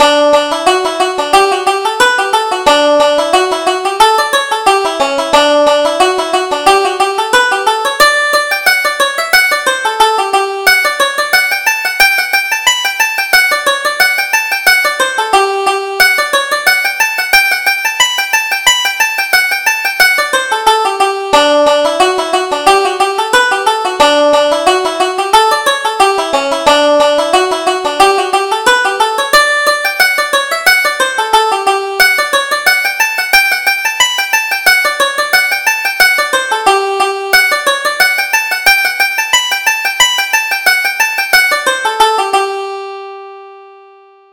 Reel: The Scolding Wife